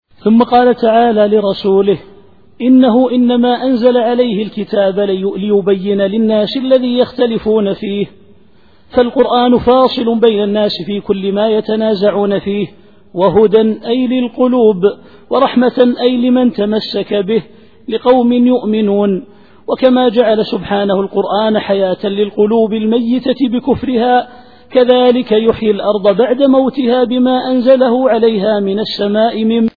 التفسير الصوتي [النحل / 65]